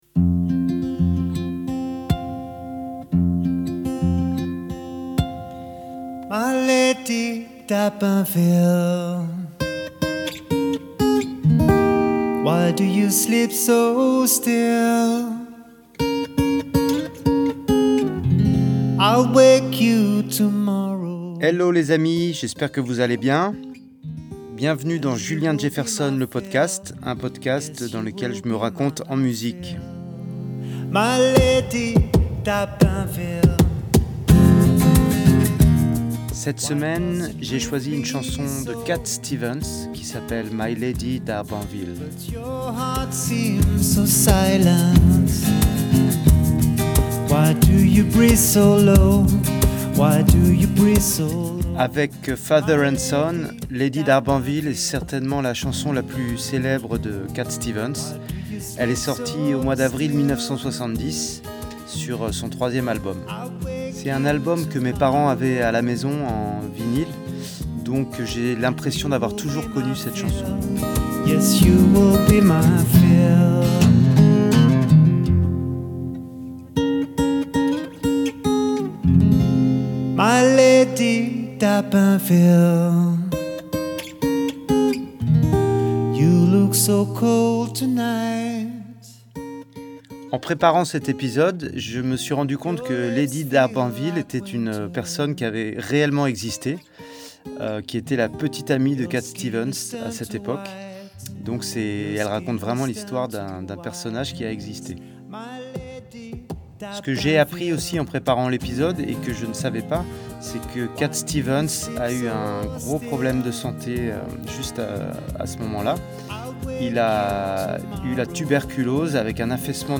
et nous en livre une version acoustique ei intimiste.